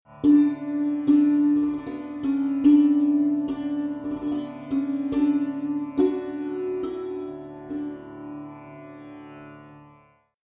Mohra: A short, gently-pulsed ‘
GLO-Mohra-Drone.mp3